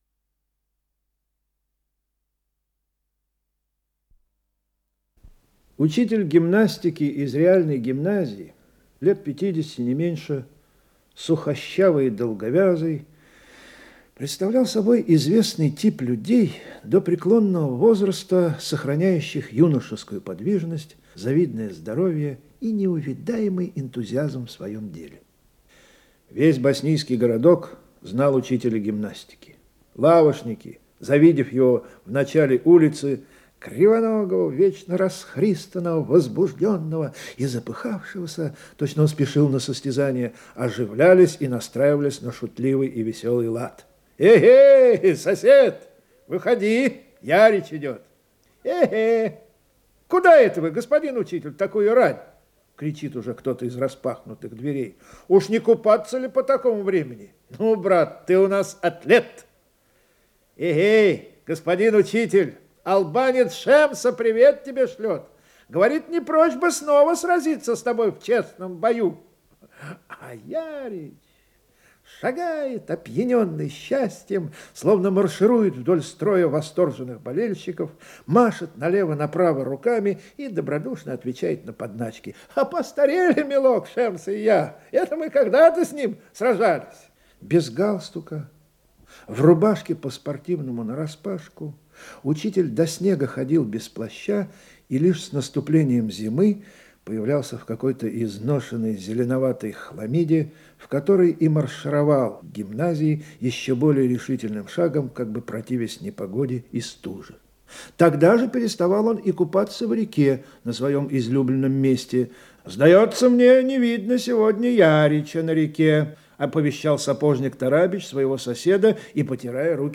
Исполнитель: Владимир Этуш - чтение